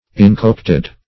Search Result for " incoacted" : The Collaborative International Dictionary of English v.0.48: Incoact \In`co*act"\, Incoacted \In`co*act"ed\, a. [L. incoactus; pref. in- not + coactus forced.